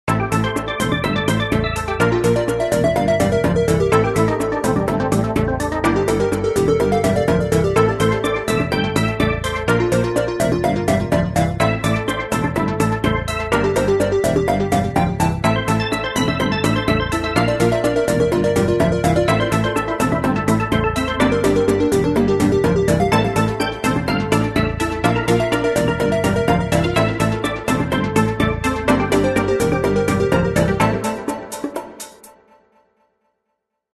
but they just sound like standard phone ringtones to me